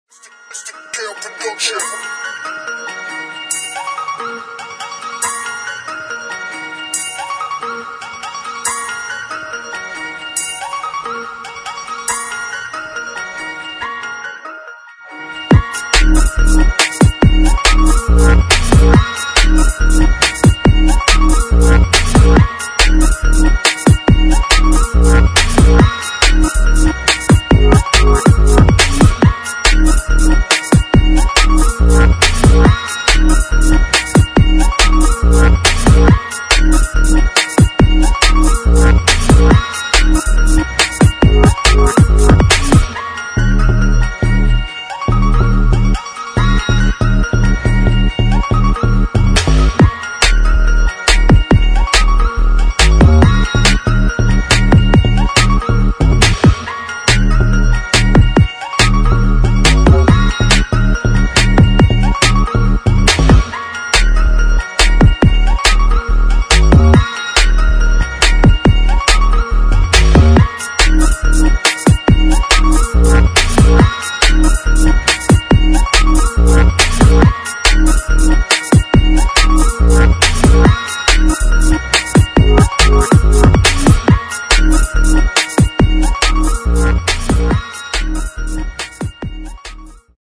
[ GRIME / DUBSTEP ]